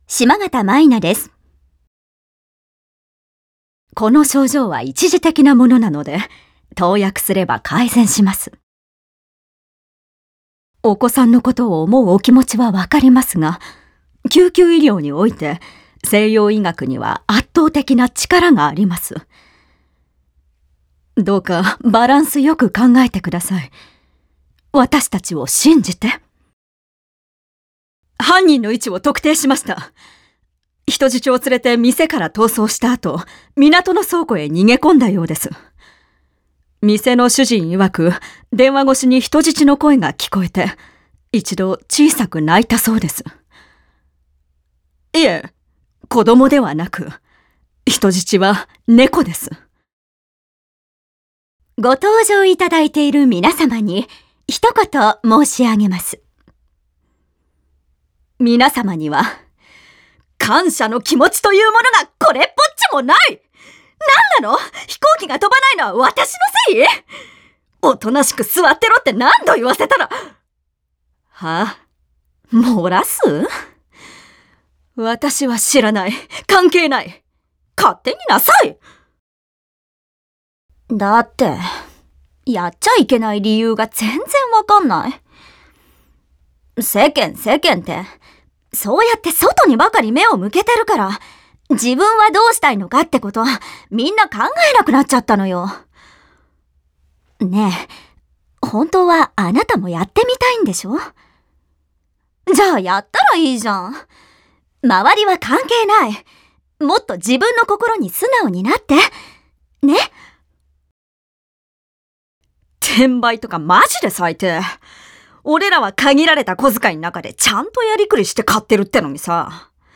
ボイスサンプル
セリフ「沙羅双樹」